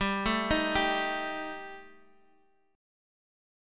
Gsus2#5 chord